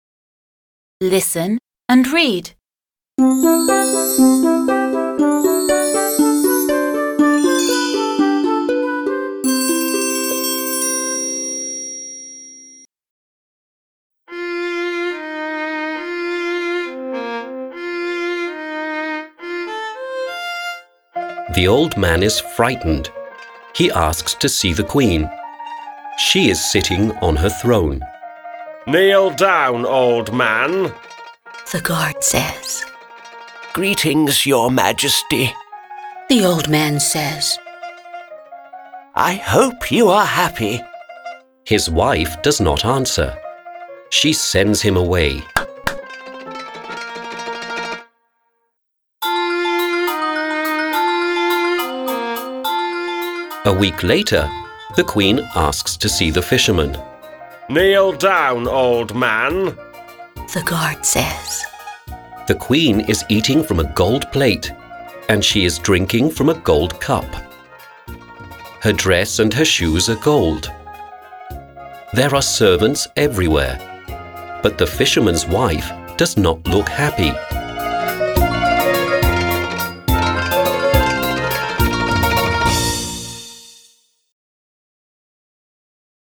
08-Story-p.-46.mp3